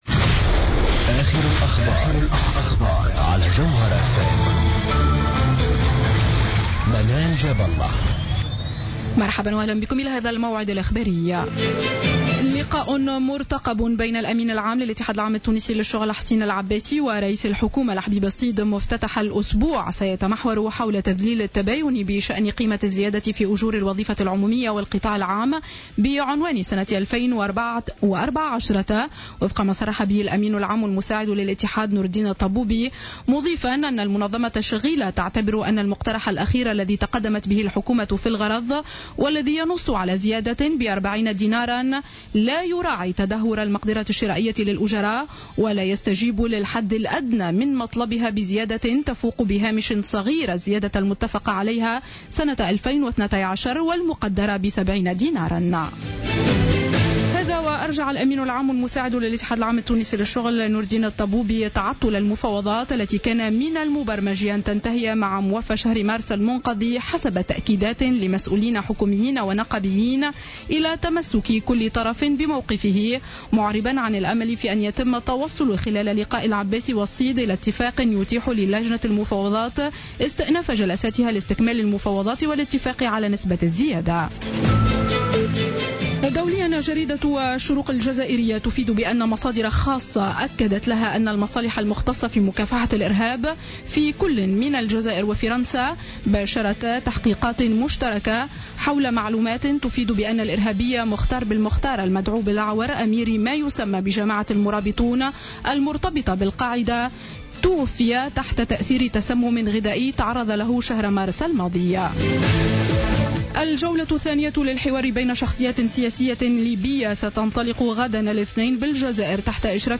نشرة أخبار السابعة مساء ليوم الأحد 12 أفريل 2015